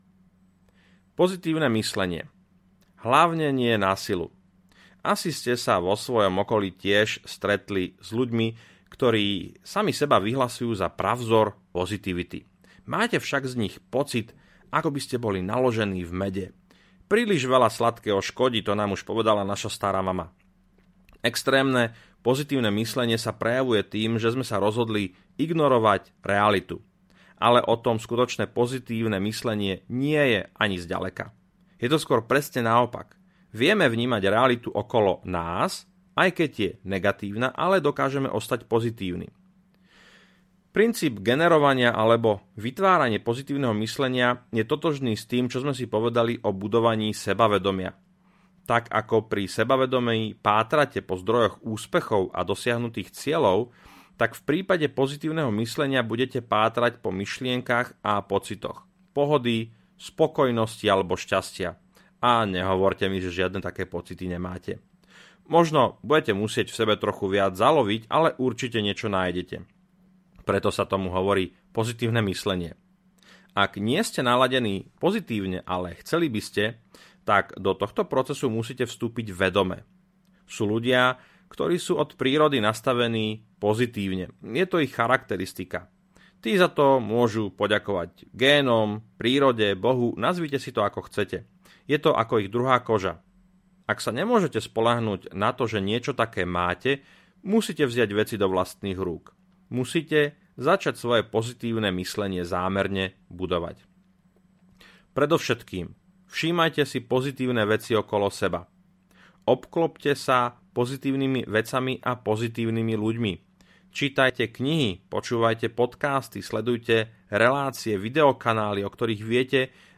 Perfektný prvý dojem - 3 sekundy rozhodnú audiokniha
Ukázka z knihy